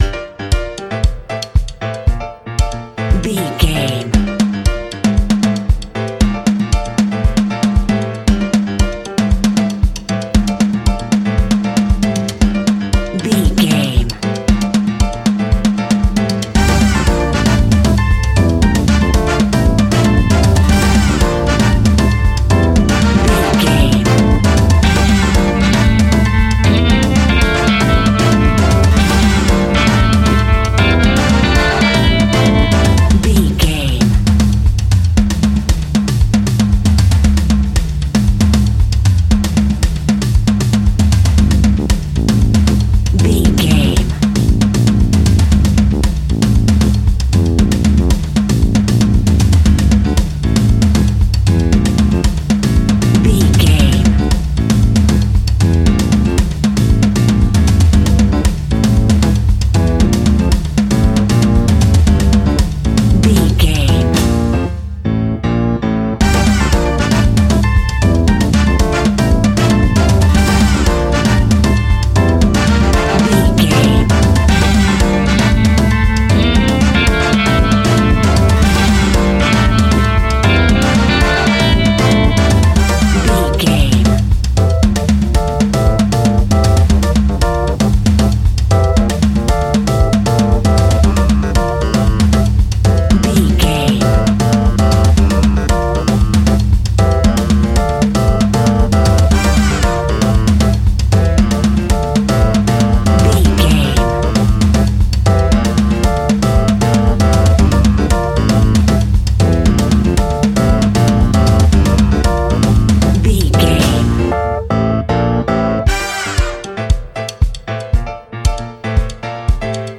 Aeolian/Minor
latin
salsa
uptempo
bass guitar
brass
saxophone
trumpet
fender rhodes
clavinet